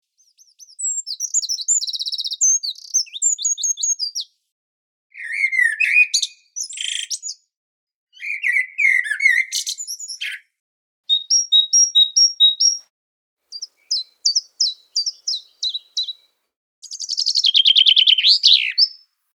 De mix van natuurlijke vogelgeluiden, delicate klokjes en rustige wateraccenten creëert een elegante, zomerse sfeer – geactiveerd door beweging.